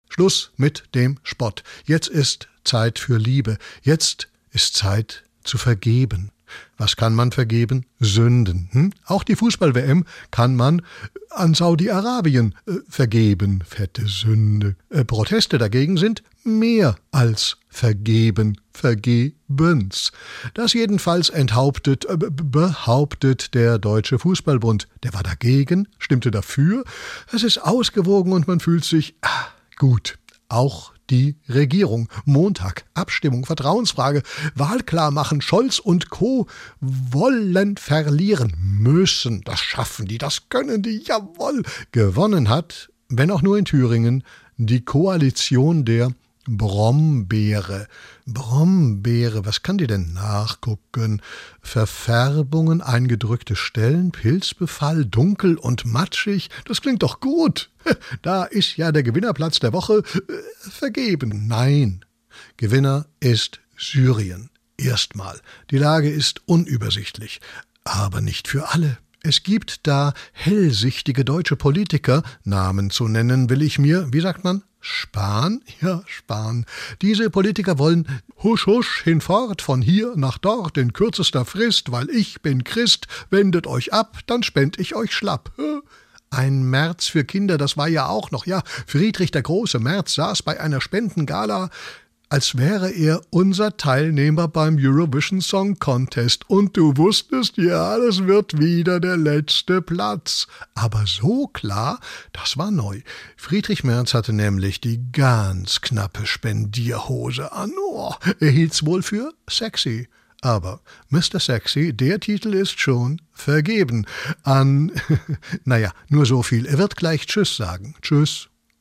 SWR3 Comedy Reuschs Wochenrückblick